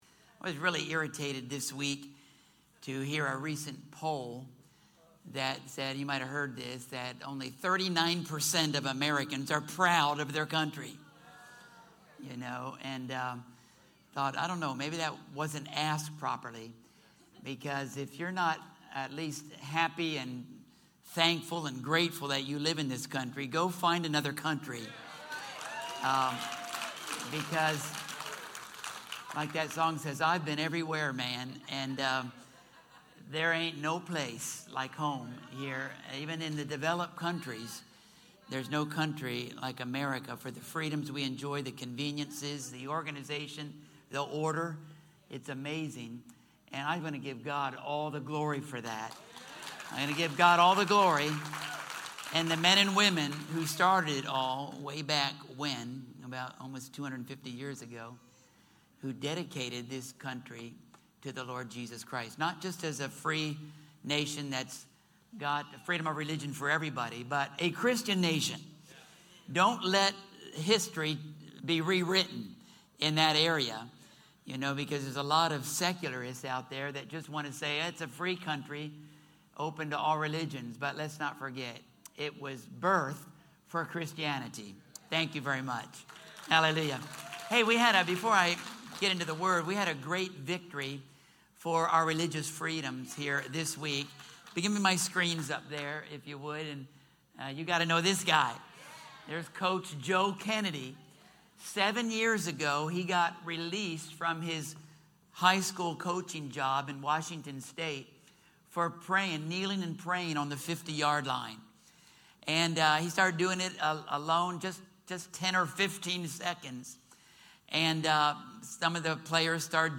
Sermons & Notes | Gospel Crusade Inc. | The Family Church at Christian Retreat | Christian Retreat Conference Center | Gospel Crusade Ministerial Association | Institute of Ministry